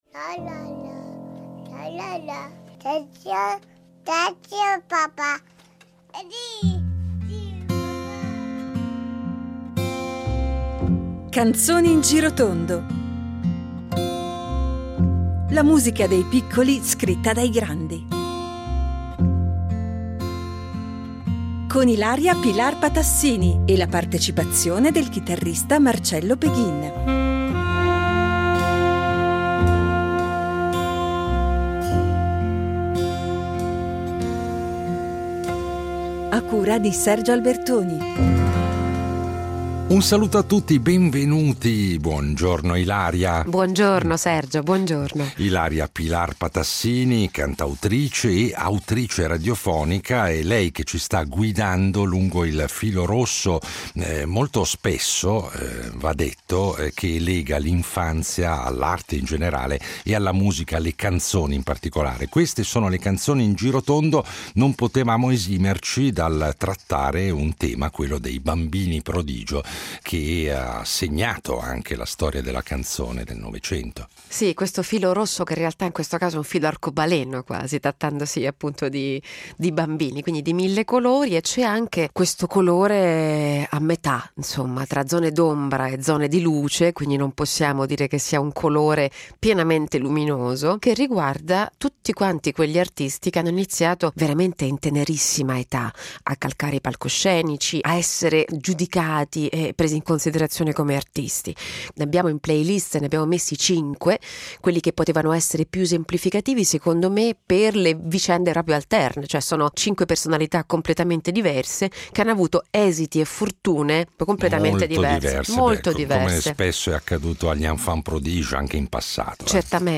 e la partecipazione del chitarrista